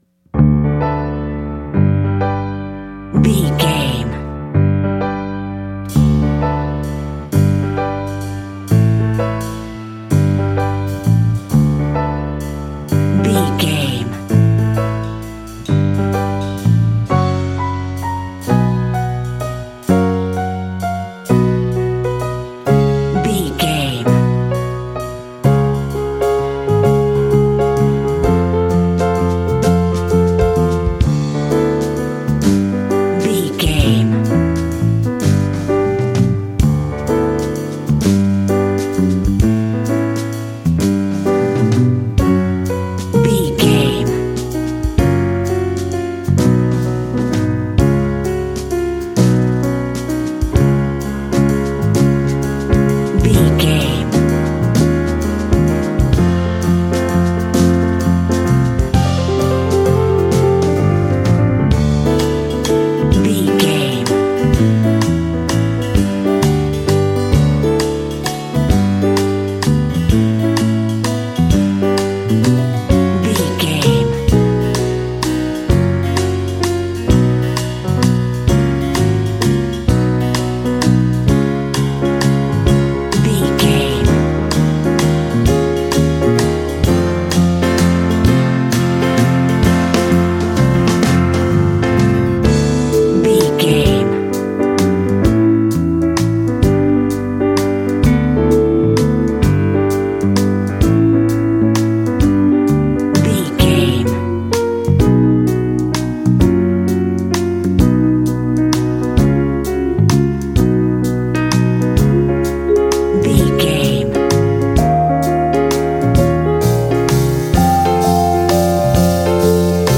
Uplifting
Ionian/Major
calm
mellow
magical
horns
brass
drums
piano
modern jazz